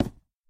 Minecraft Wood Break Place Sound Effect – Free MP3 Download | SoundboardFun
minecraft wood break place
minecraft wood break place.mp3